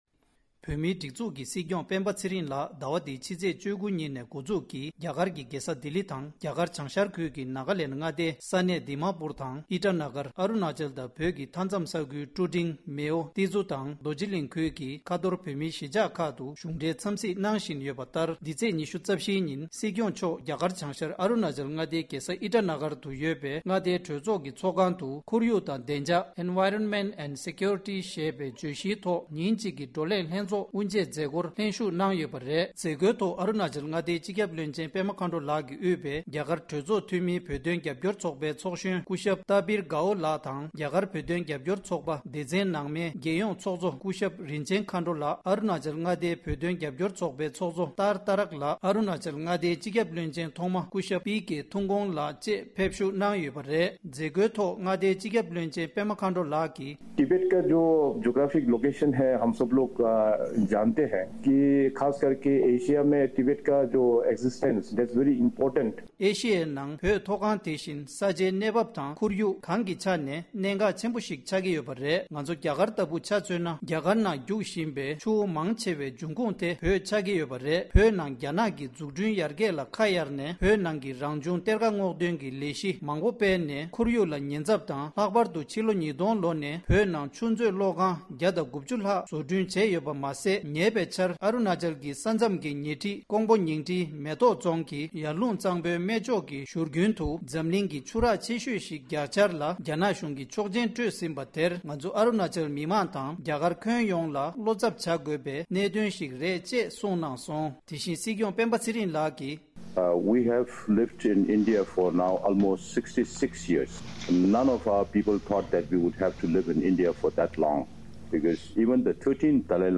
སྒྲ་ལྡན་གསར་འགྱུར། སྒྲ་ཕབ་ལེན།
ཨི་ཊཱ་ན་གར་དུ་ཡོད་པའི་མངའ་སྡེའི་གྲོས་ཚོགས་ཀྱི་ཚོགས་ཁང་དུ་ཁོར་ཡུག་དང་བདེ་འཇགས་ཚོགས་འདུའི་སྐབས། ༢༠༢༥།༠༡།༢༤ ཉིན།